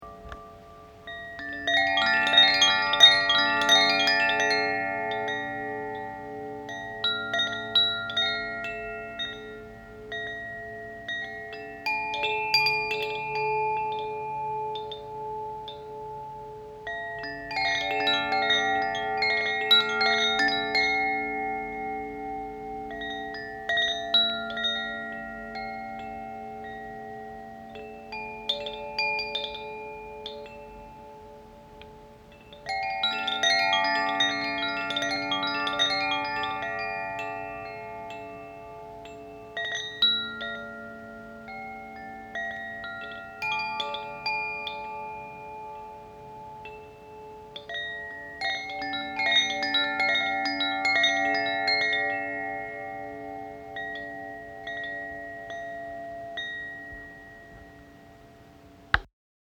Carillon Koshi • Aqua
Il en existe 4 types, chacun accordé sur une gamme différente et inspiré par les quatre éléments.
Chaque carillon est accordé sur une gamme spécifique, créant des mélodies uniques et harmonieuses qui reflètent les caractéristiques de l’élément correspondant.
Carillon-Aqua.mp3